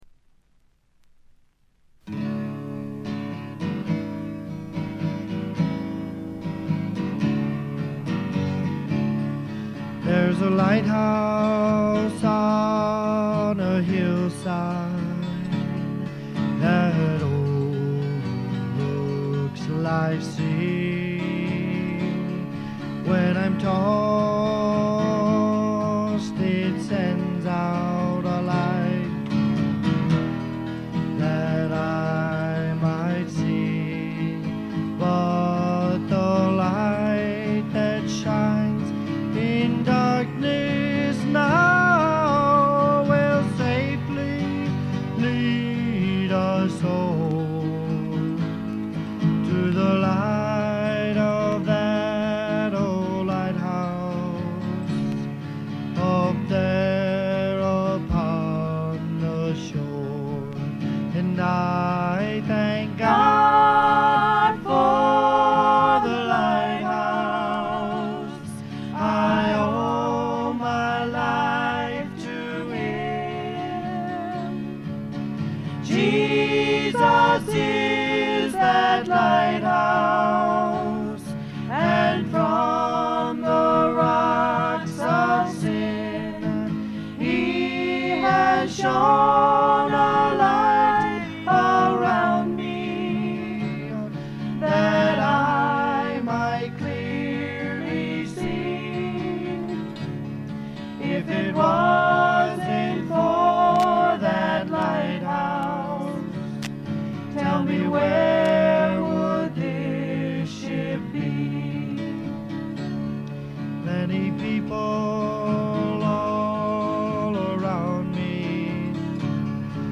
知られざるクリスチャン・フォーク自主制作盤の快作です。
試聴曲は現品からの取り込み音源です。